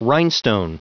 Prononciation du mot rhinestone en anglais (fichier audio)
Prononciation du mot : rhinestone